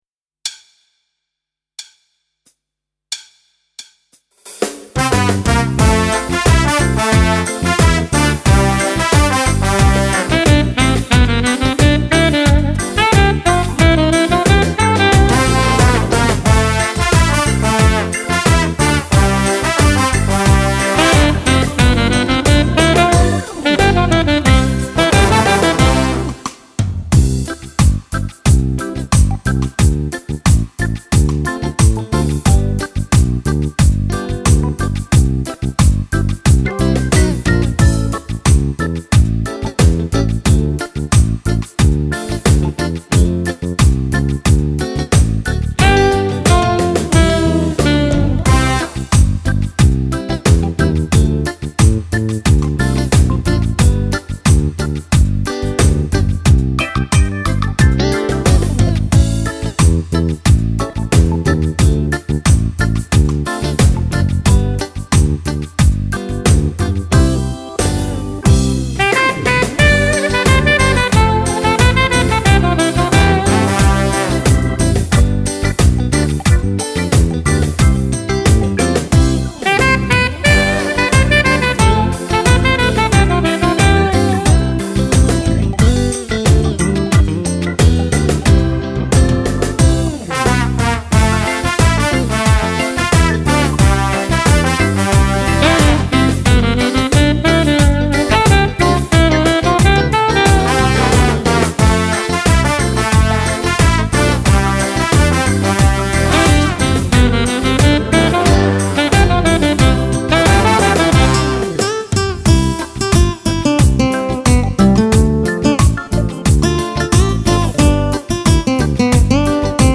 Bajon-reggae
Fisarmonica